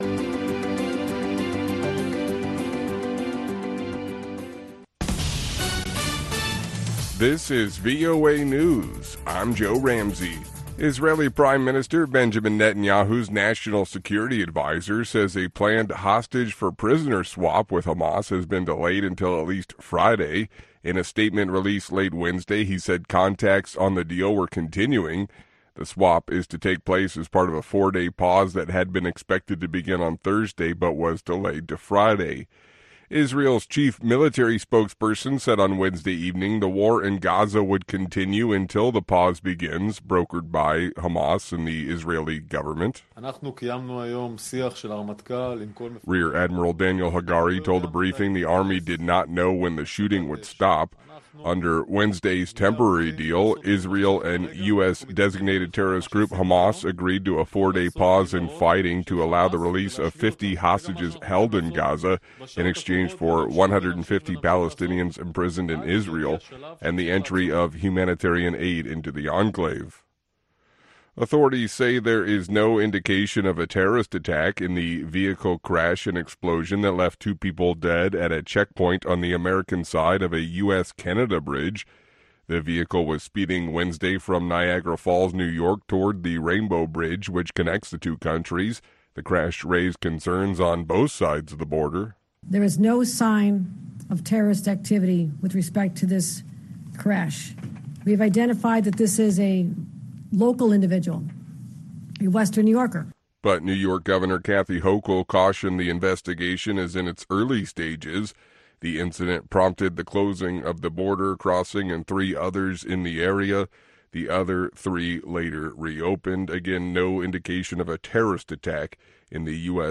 Two Minute Newscast